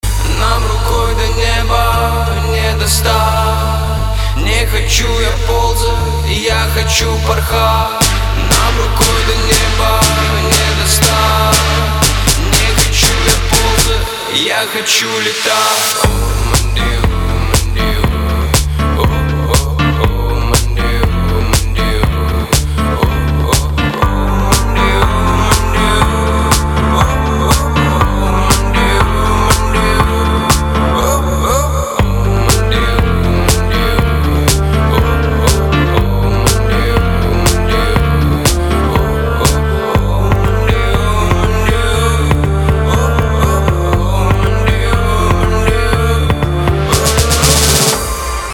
• Качество: 320, Stereo
мужской вокал
deep house
спокойные
Стиль: Deep house, Vocal House